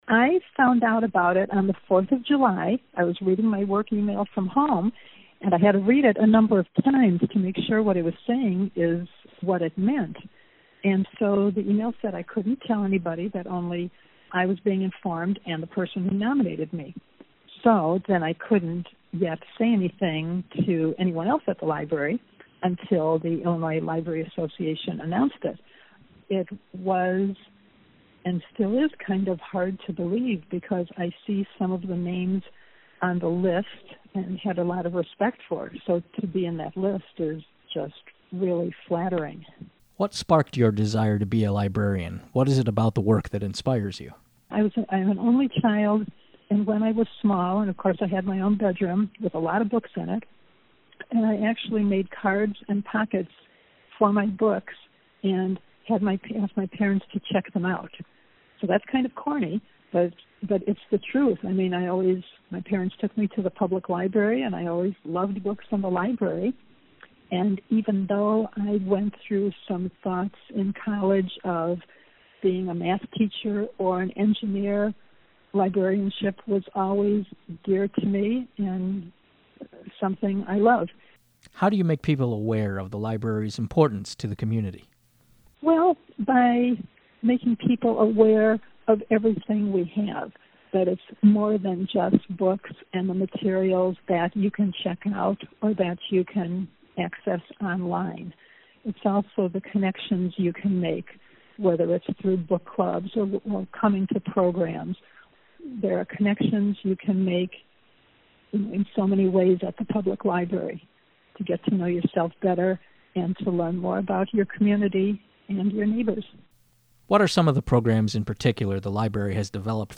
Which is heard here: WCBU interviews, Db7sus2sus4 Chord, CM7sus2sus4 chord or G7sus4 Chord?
WCBU interviews